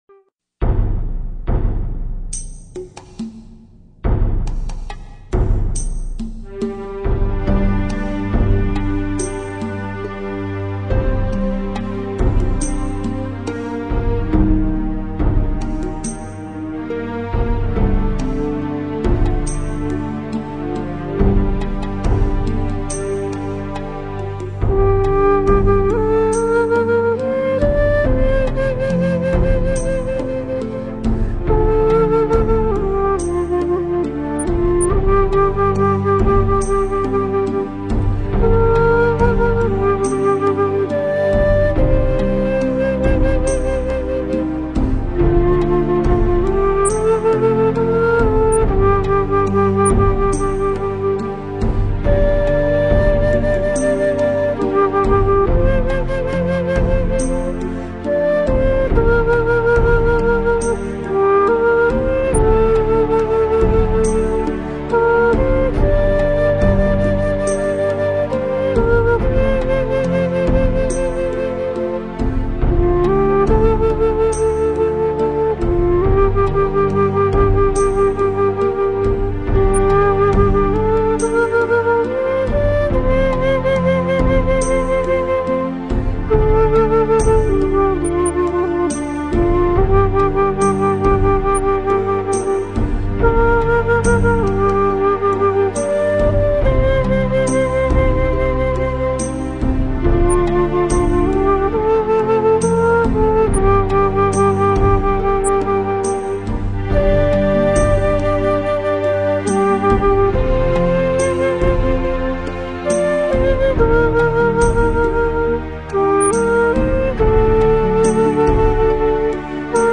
听一曲断情 写一段殇 只为梦碎：《断情殇》（洞箫&铃鼓） 激动社区，陪你一起慢慢变老！